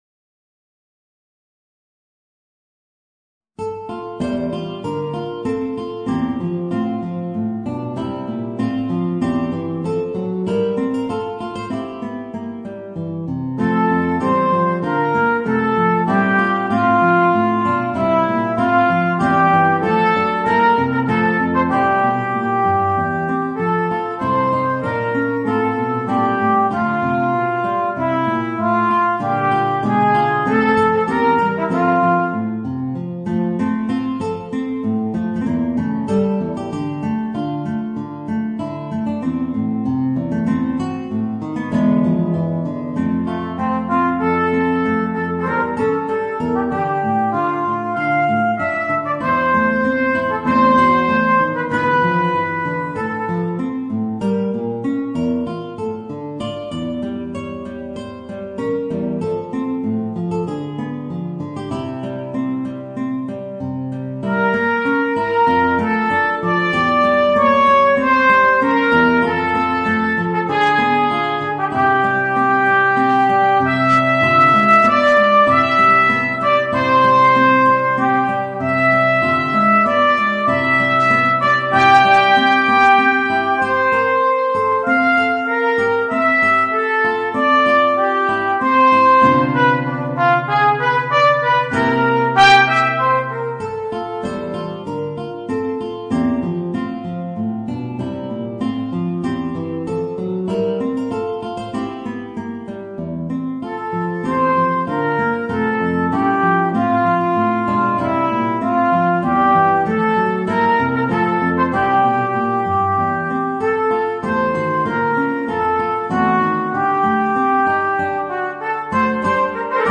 Voicing: Guitar and Trumpet